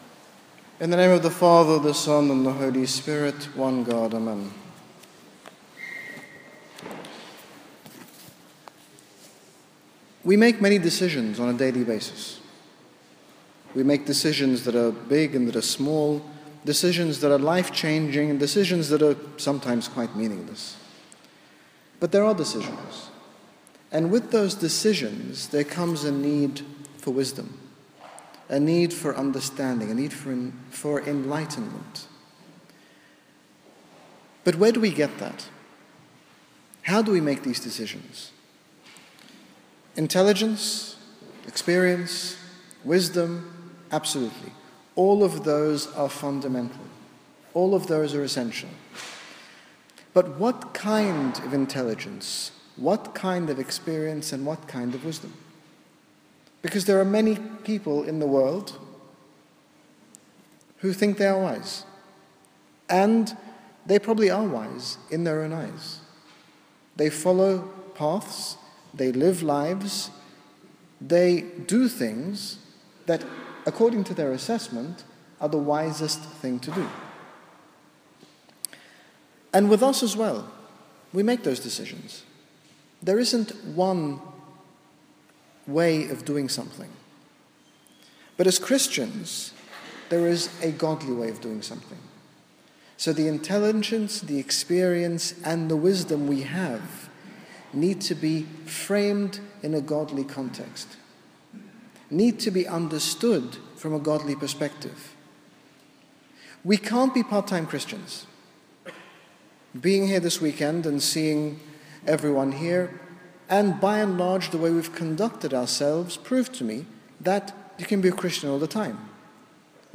In this short sermon, His Grace Bishop Angaelos, General Bishop of the Coptic Orthodox Church in the United Kingdom, speaks about the importance of making Godly decisions on a daily basis.